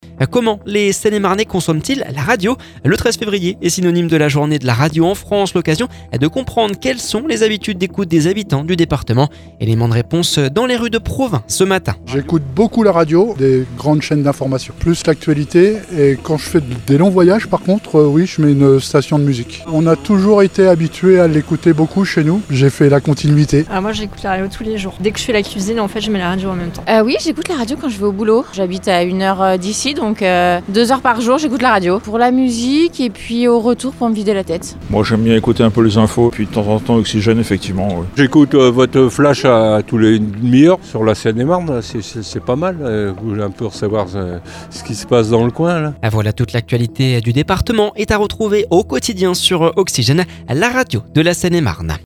Le 13 février est synonyme de journée de la radio en France, l’occasion de comprendre quelles sont les habitudes d’écoutent des habitants du département, éléments de réponses dans les rues de Provins ce matin.